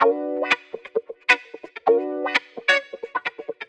Electric Guitar 03.wav